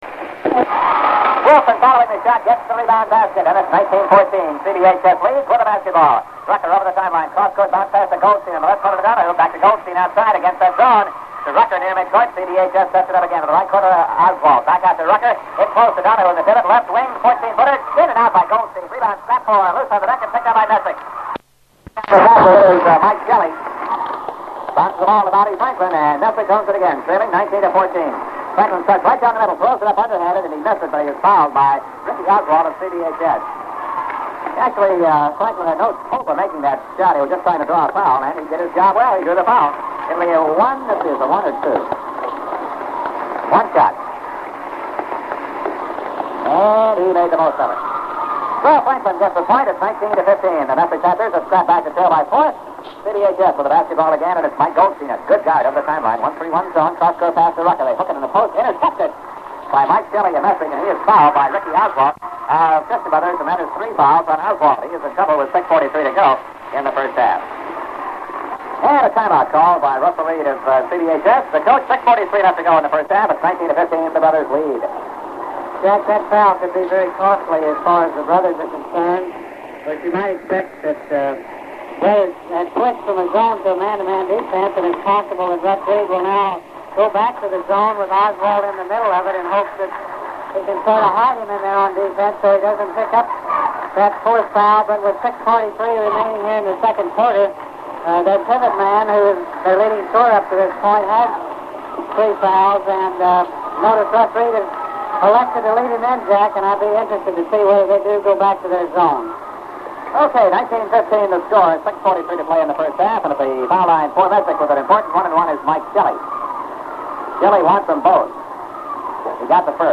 This is a recorded radio broadcast of most of the 1968-69 regular season basketball game between Messick and Christian Brothers High School. It comes in several minutes into the first half and ends about a minute before the finish.
Sound quality is very poor but understandable. (Remember this is a 1969 radio broadcast copied from reel-to-reel and then minicassette before it was converted to digital format.)